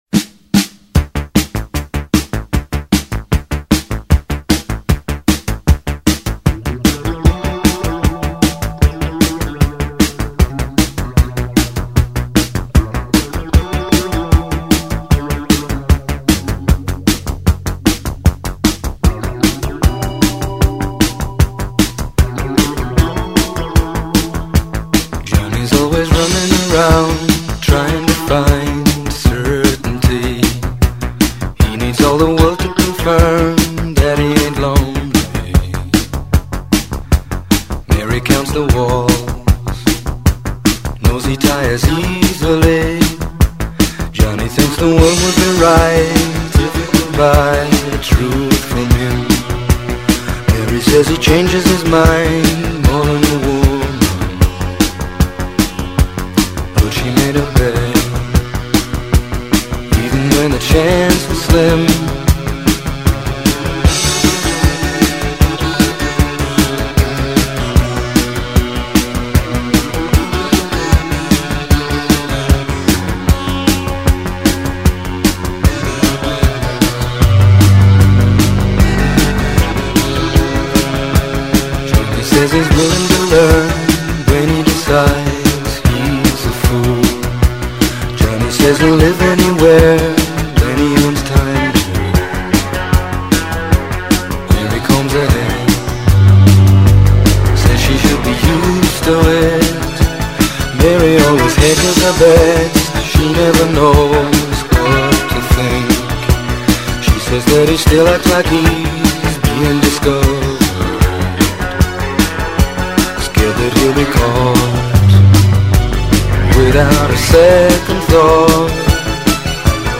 Today’s choice is one of his disco/electro track